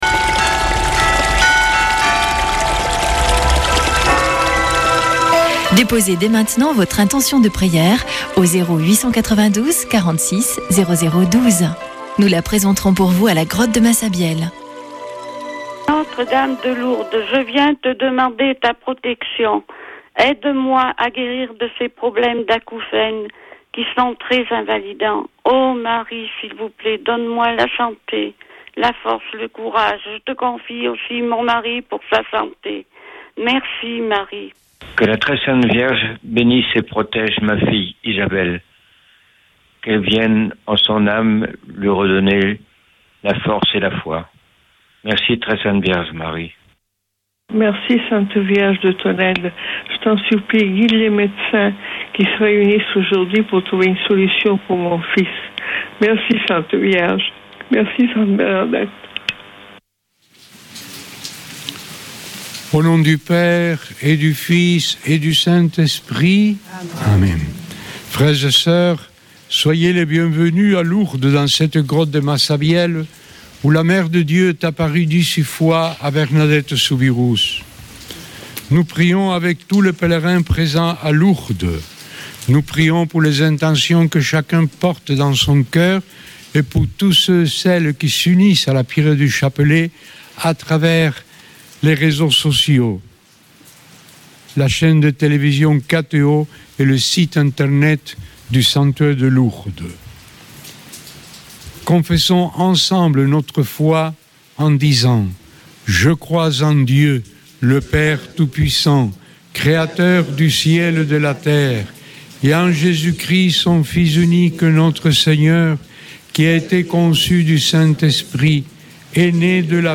Accueil \ Emissions \ Foi \ Prière et Célébration \ Chapelet de Lourdes \ Chapelet de Lourdes du 16 févr.
Une émission présentée par Chapelains de Lourdes